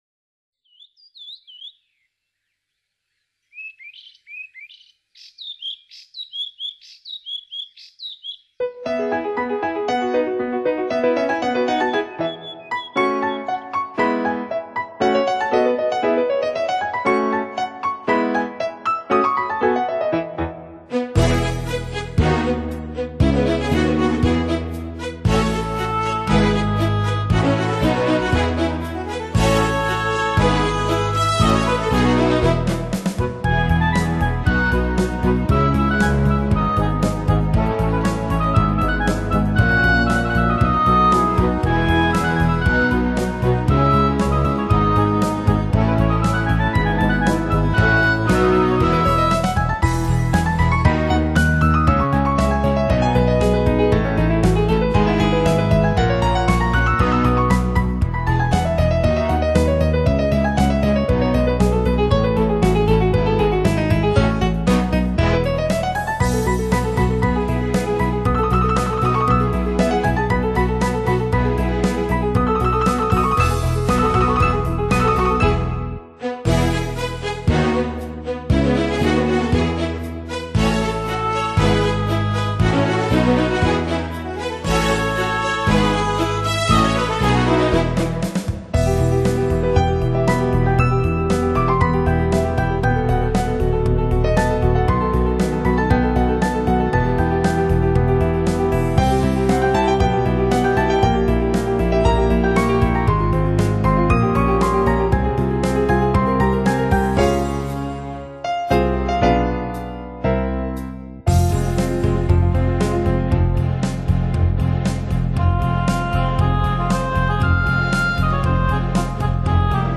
美妙的旋律， 欢快的节奏， 让人觉得心情明朗欢快起来， 这叫人倍感清新的音乐， 更有让人倍感新鲜兴奋的时尚气息， 美丽的心情随着这乐曲声在流淌......